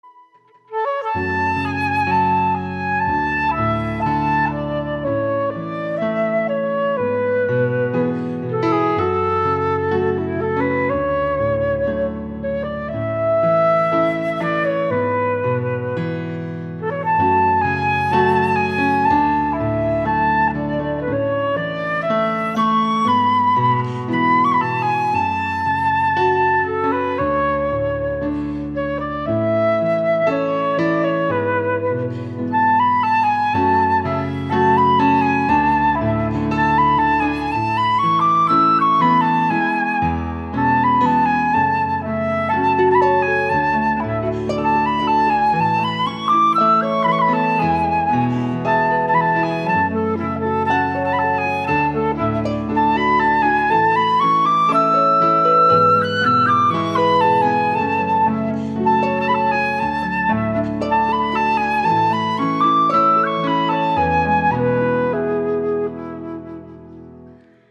Viento Madera
FLAUTA
Flauto.mp3